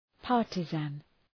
Προφορά
{‘pɑ:rtızən}